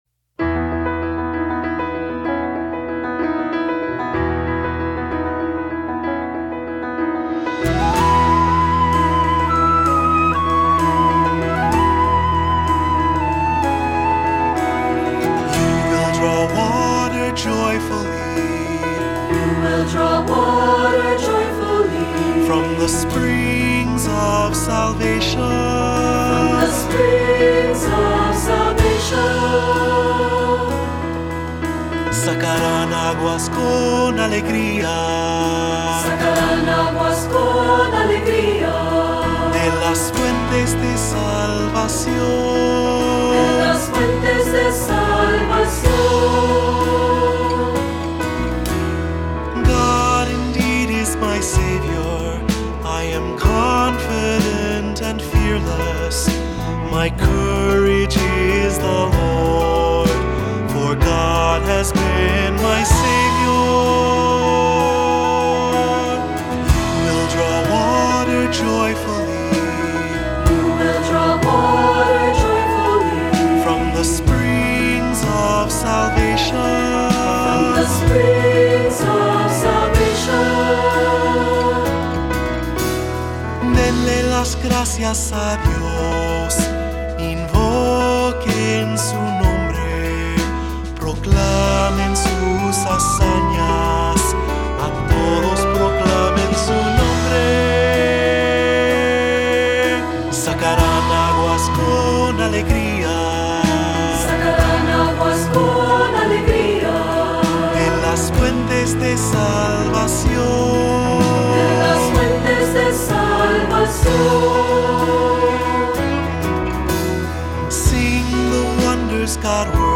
Voicing: Unison; Cantor; Assembly